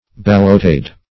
Search Result for " ballotade" : The Collaborative International Dictionary of English v.0.48: Ballotade \Bal"lo*tade`\ (b[a^]l"l[-o]*t[aum]d` or b[a^]l"l[-o]*t[=a]d`), n. [F. ballottade, fr. ballotter to toss.